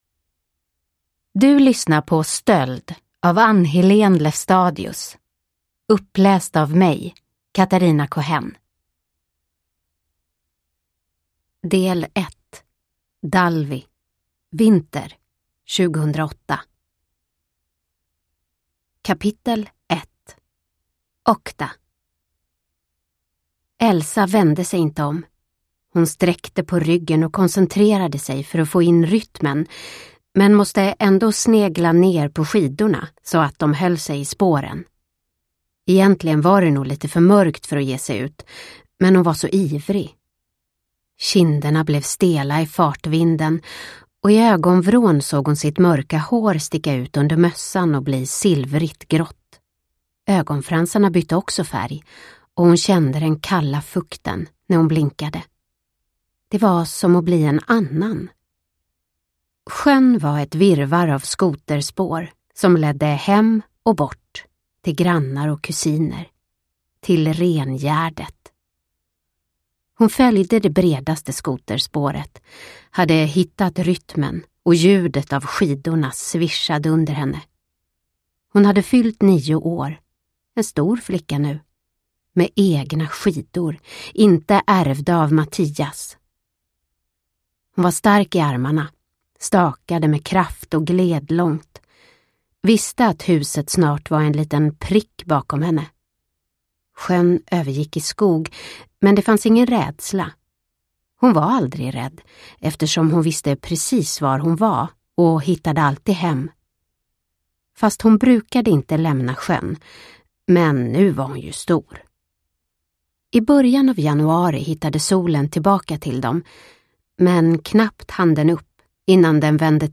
Stöld – Ljudbok – Laddas ner